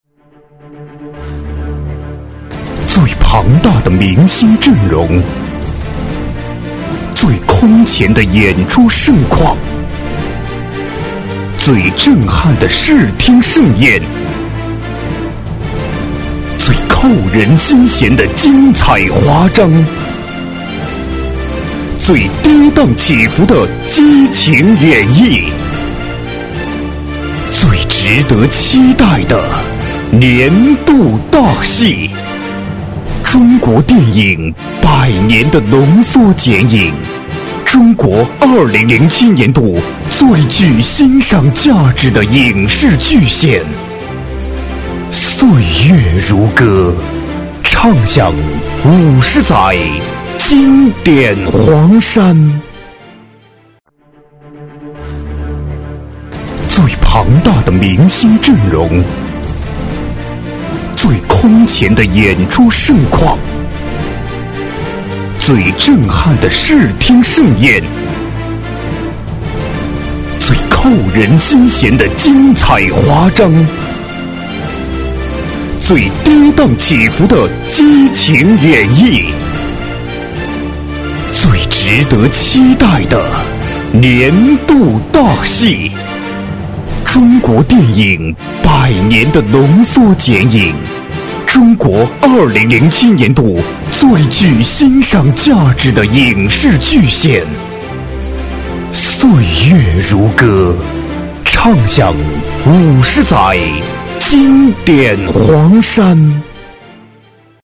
• 男S337 国语 男声 专题片-黄山-大气、震撼 大气浑厚磁性|沉稳